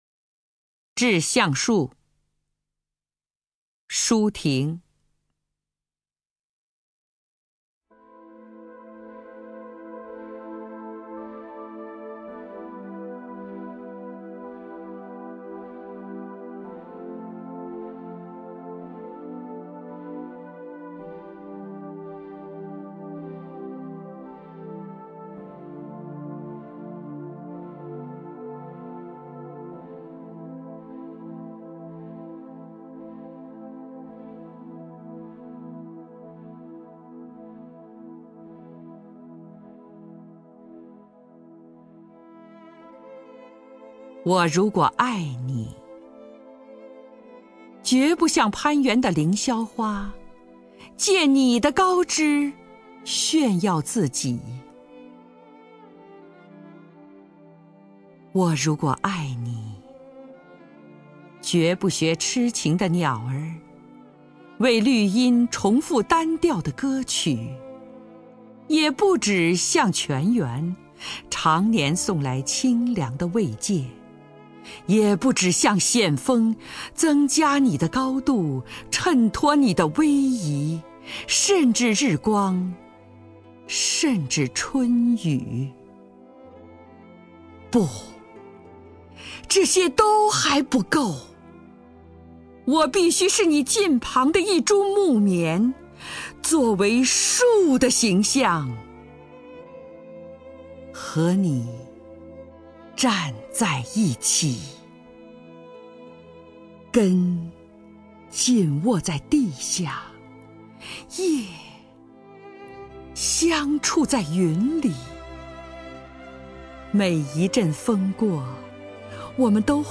首页 视听 名家朗诵欣赏 张筠英
张筠英朗诵：《致橡树》(舒婷)